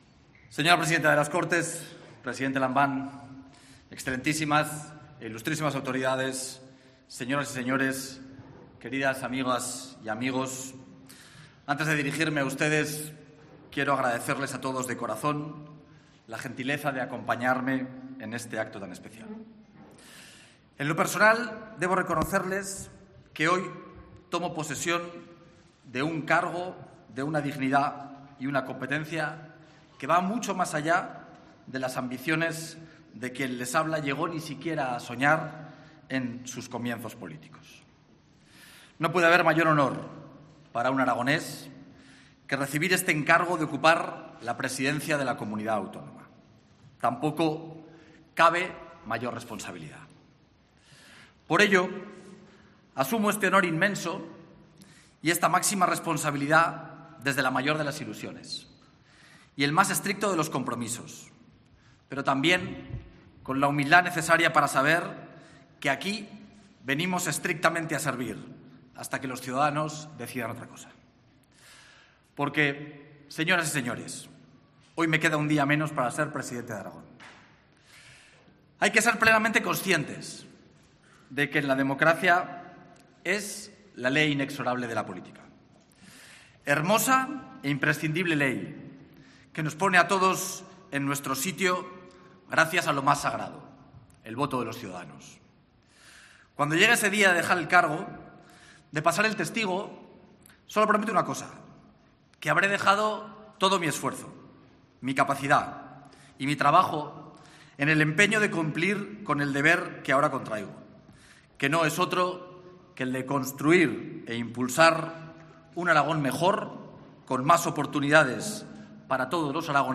Escucha el discurso íntegro del nuevo presidente de Aragón, Jorge Azcón.